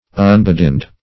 unbedinned - definition of unbedinned - synonyms, pronunciation, spelling from Free Dictionary
Unbedinned \Un`be*dinned"\, a. Not filled with din.